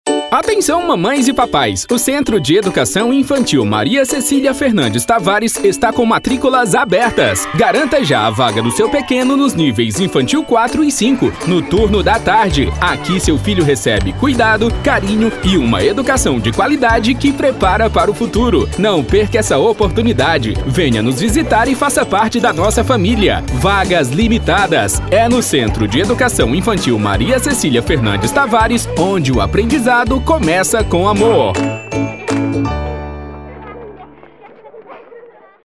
Ceará
Demo Escola Padrão Tom Medio: